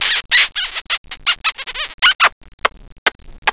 Des petits bruits :